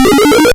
ItemAppear.wav